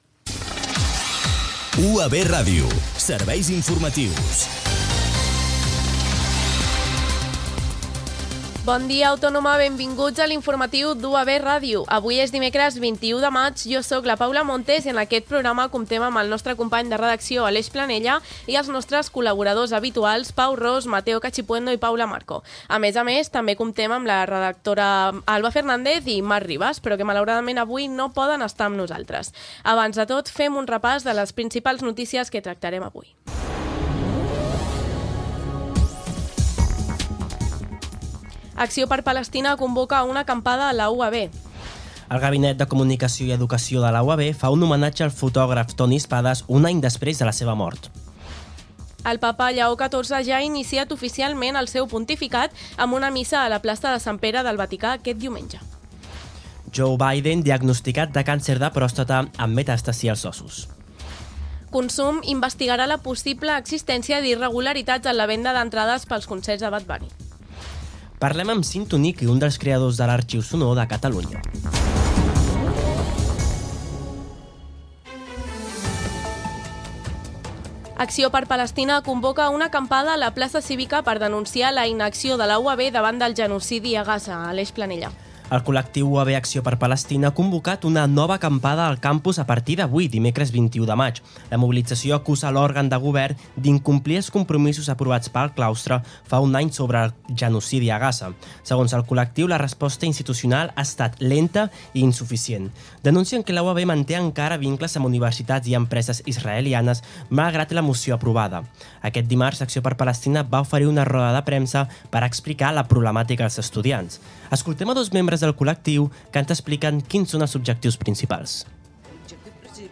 Careta del programa
Gènere radiofònic Informatiu